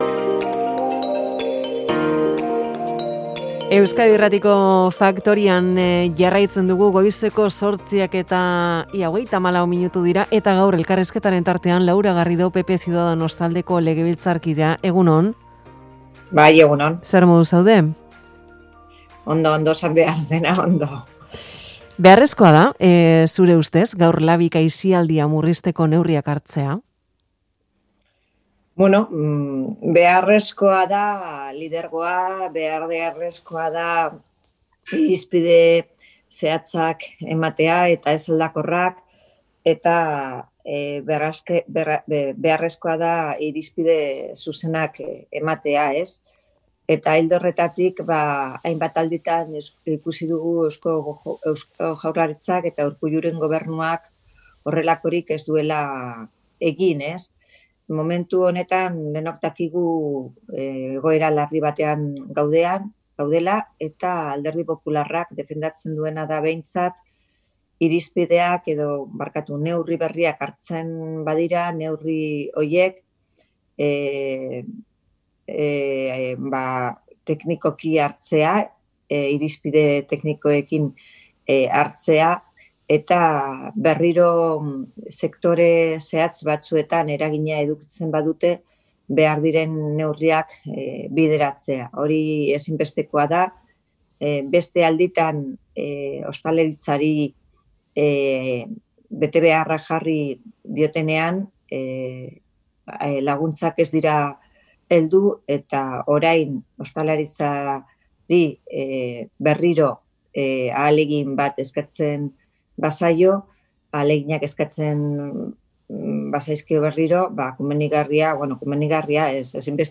Audioa: Alderdi Popularreko legebiltzarkide eta Euskadiko bozeramaleak ziurtzat jo du bi alderdiek EAEko aurrekontuetarako lortu duten akordioaren atzean Estatutua berritzeko urratsen bat dagoela. Jeltzaleek koalizio abertzalearekiko duten jarrera aldaketaz harrituta hitz egin du 'Faktoria'n.